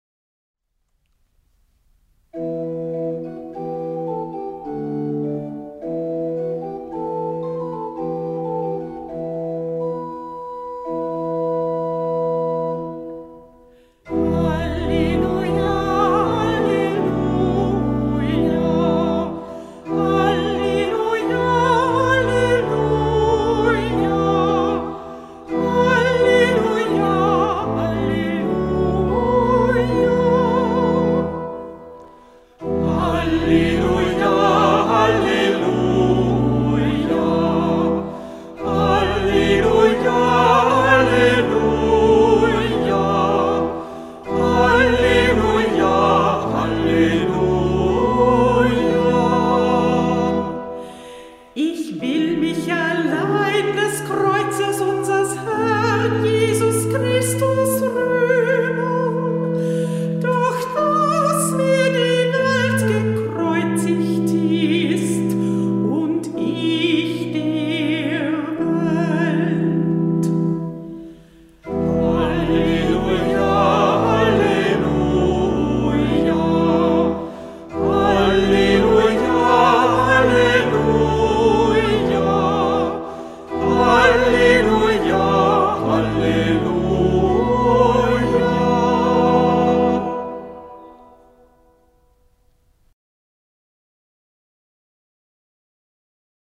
Ruf vor dem Evangelium - September 2024
Kantorin der Verse